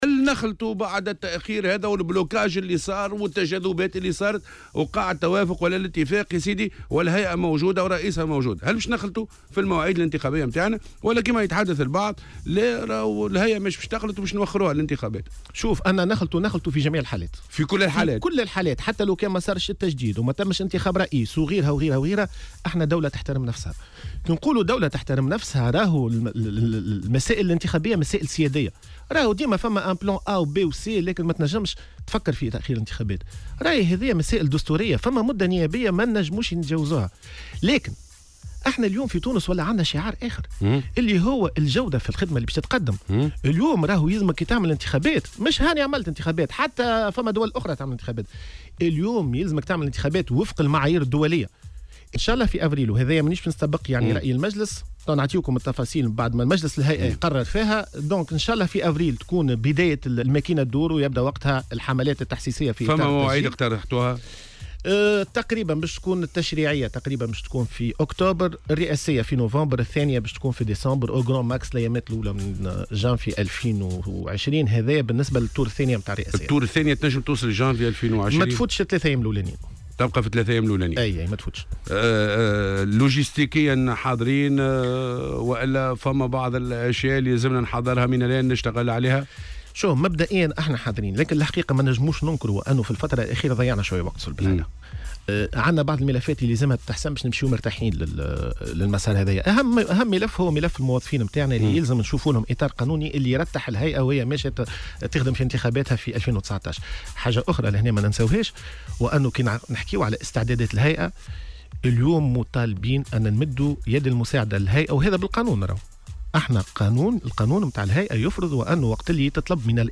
وتحدث في مداخلة له اليوم في برنامج "صباح الورد" على "الجوهرة أف أم" عن الروزنامة الأولية للانتخابات، حيث من المقرّر إجراء الانتخابات التشريعية خلال شهر أكتوبر والرئاسية في شهر نوفمبر القادم (الدورة الأولى) و آخر ديسمبر (الدورة الثانية).